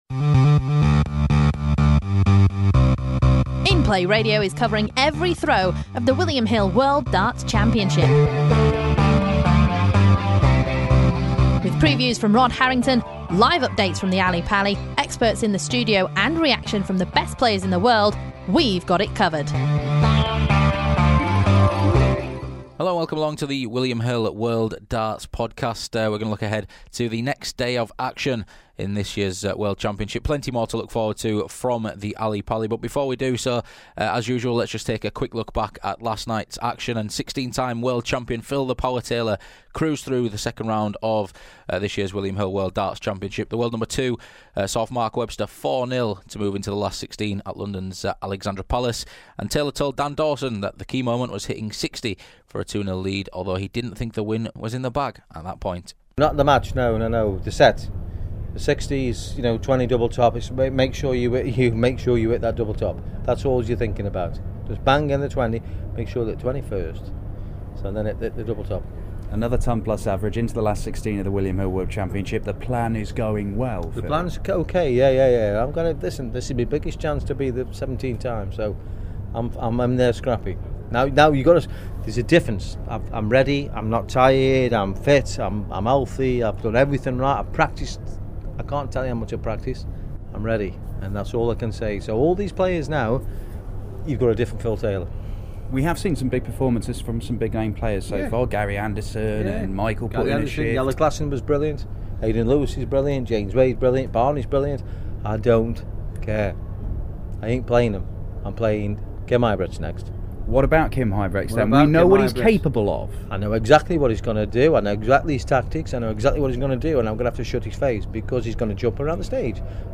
We also hear from the other winners from Sunday night before Rod Harrington gives us his best bet for Monday.